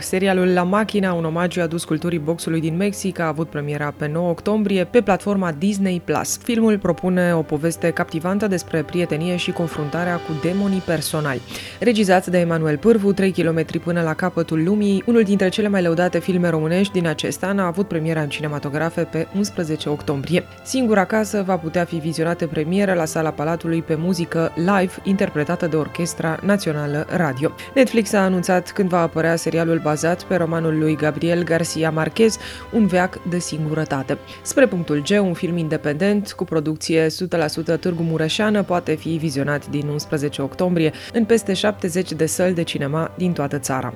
știri proaspete din lumea cinematografiei, alternate de melodii celebre.